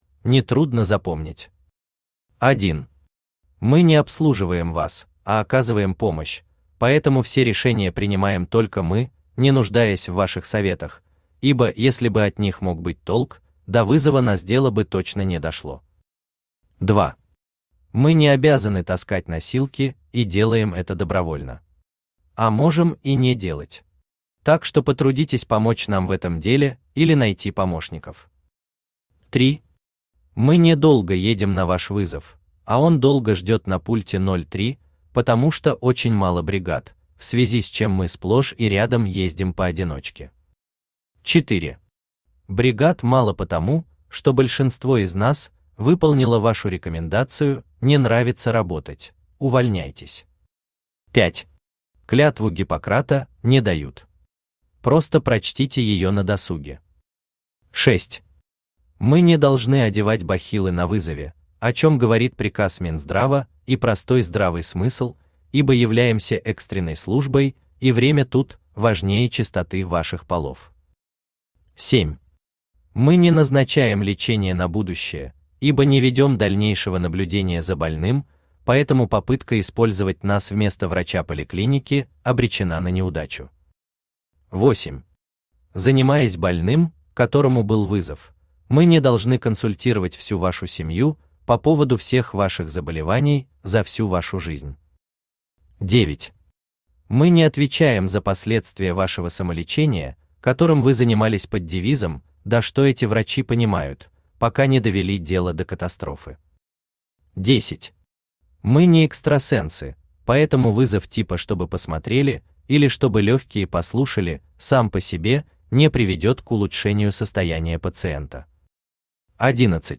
Том 4 Автор Геннадий Анатольевич Бурлаков Читает аудиокнигу Авточтец ЛитРес.